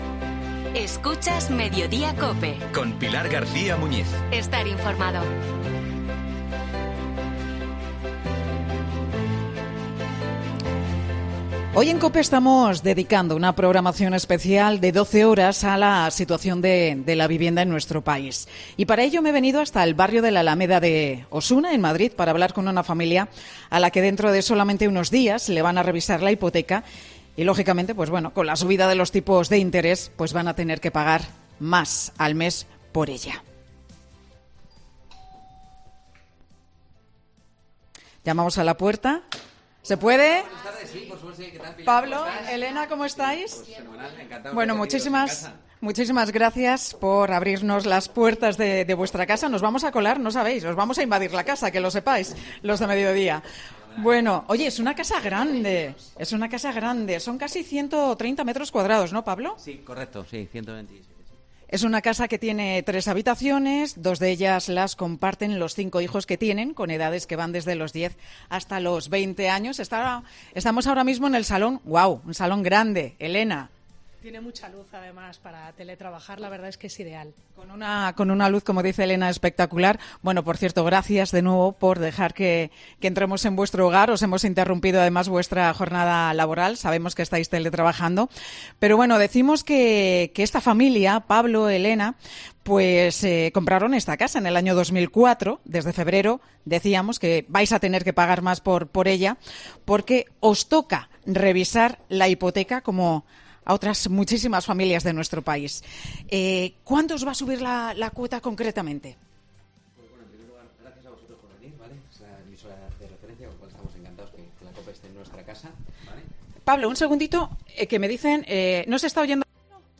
Una familia recibe en su casa a Mediodía COPE para contar cómo afrontan la subida de precios de la vivienda